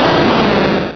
Cri d'Entei dans Pokémon Rubis et Saphir.